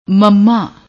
thirtieth letter of Gurmukhi script representing the bilabial nasal consonant [m]